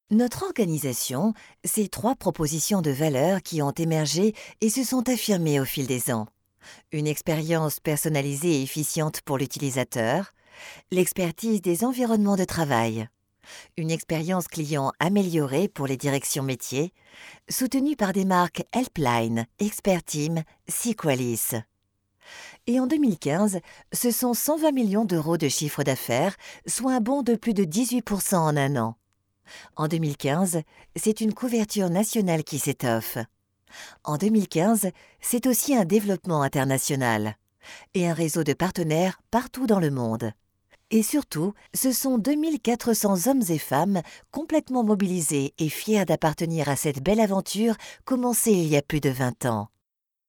20 years of experience of voice over, on radio Tv and other types of recordings
Sprechprobe: Industrie (Muttersprache):